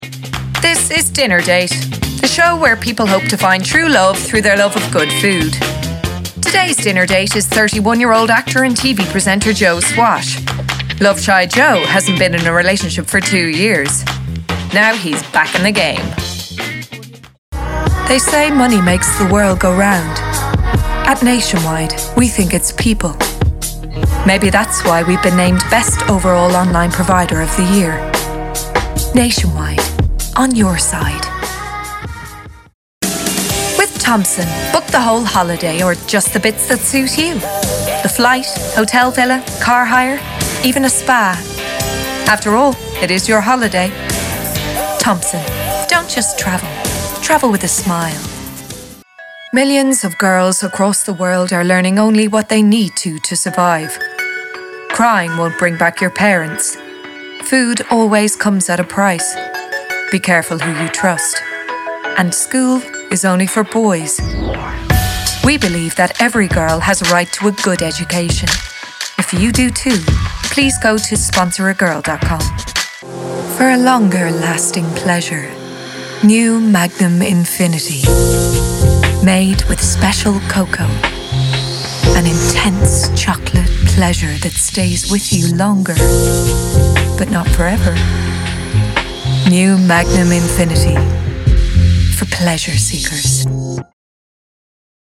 Voice samples